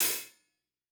TC2 Live Hihat1.wav